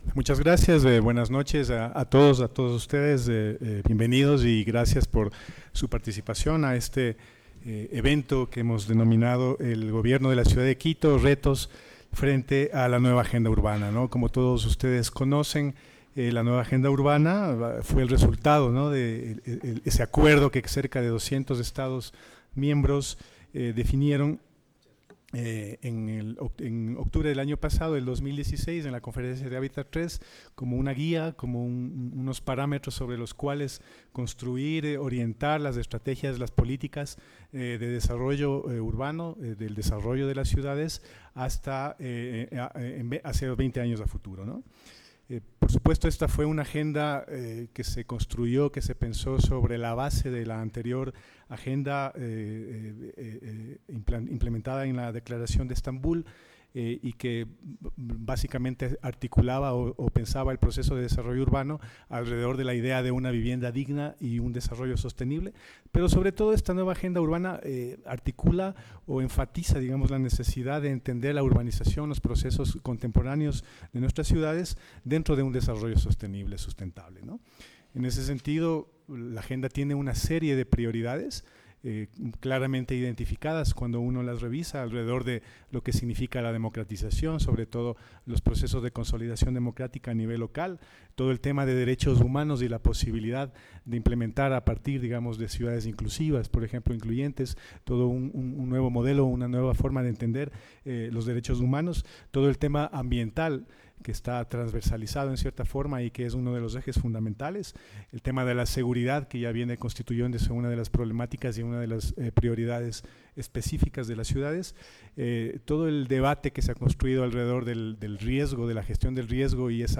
Augusto Barrera. Exalcalde de Quito (2009-2014).